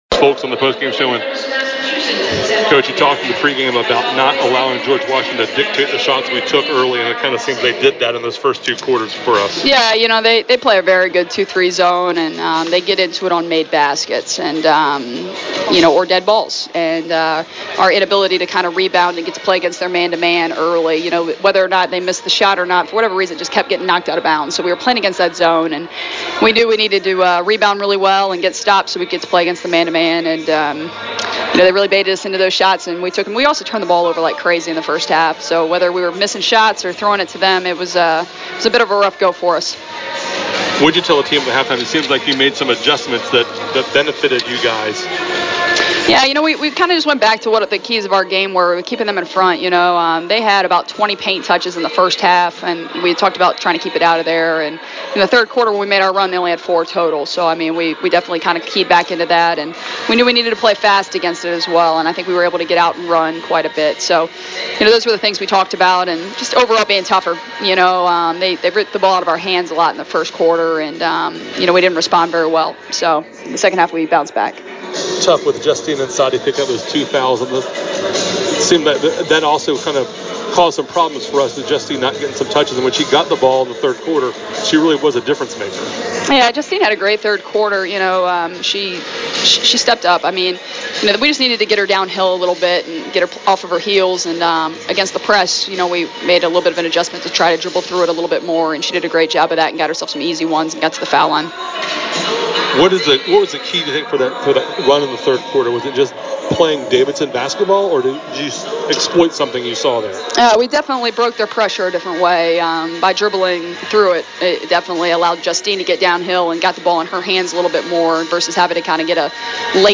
Postgame Comments
Post Game GW Women.mp3